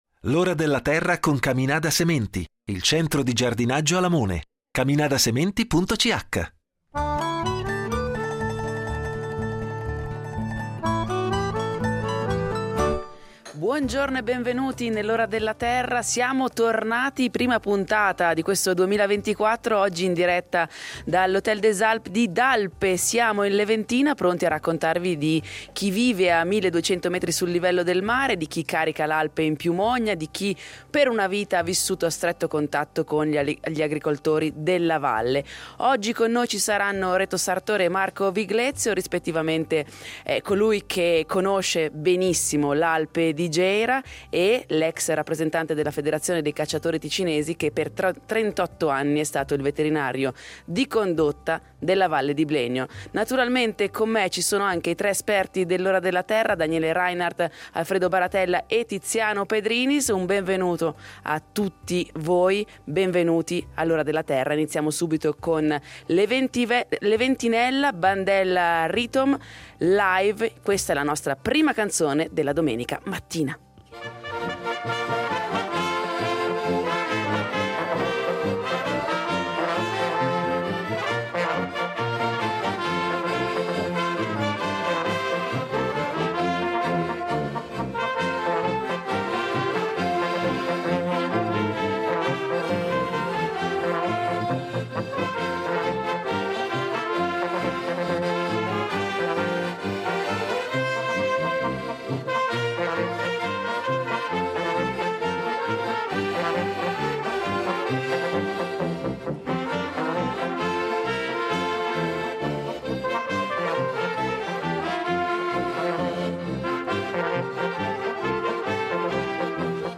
In diretta da Dalpe